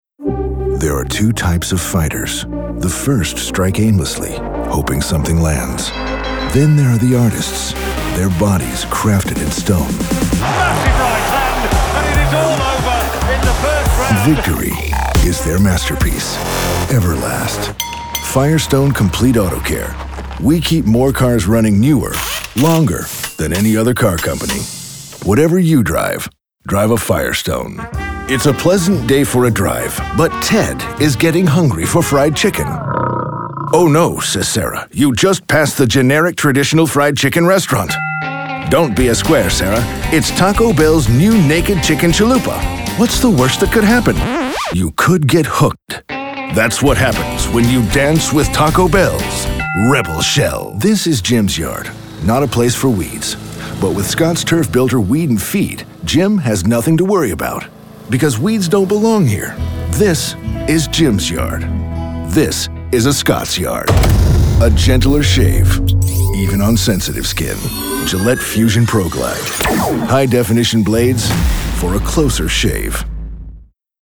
Commercial
His rugged baritone is well-traveled, trustworthy, believable and altogether gripping. It can be intimate and inviting, or intense and commanding.